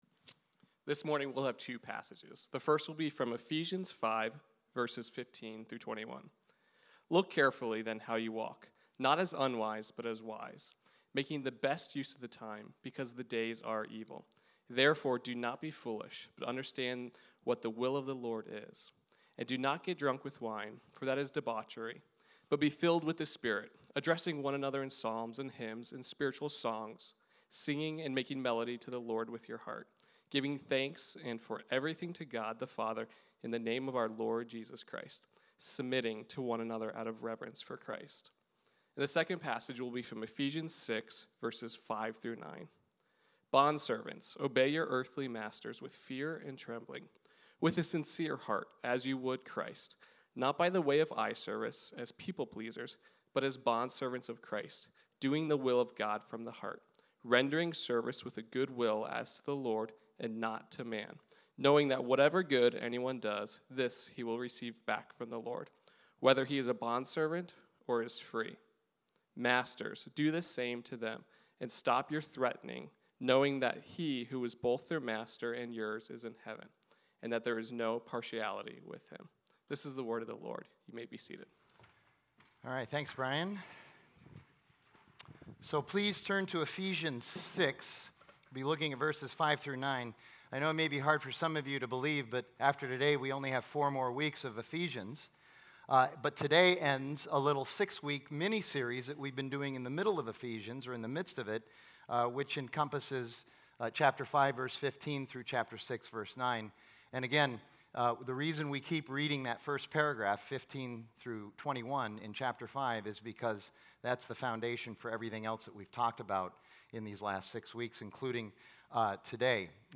Ephesians: Spirit-Filled Work from Redemption Arcadia Sermons.